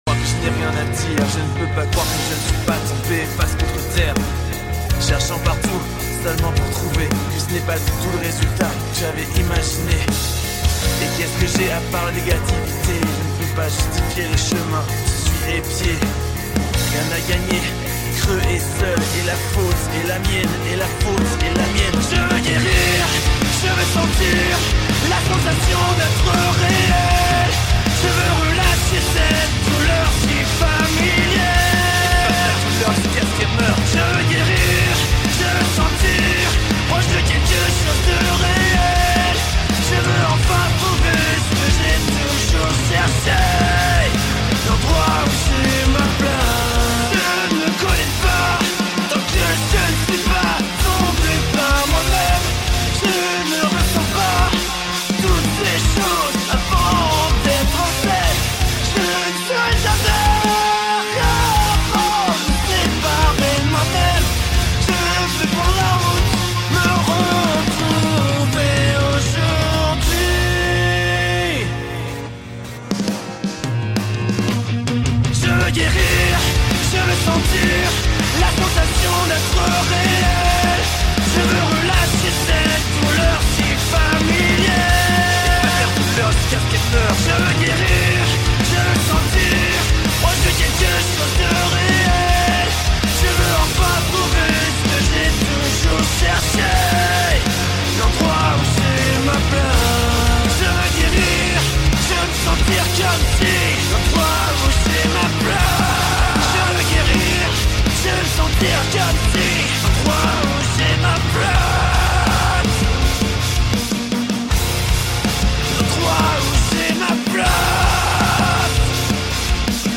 drum cover
french singing cover
🥁 Electronic drum kit
Drummer 🥁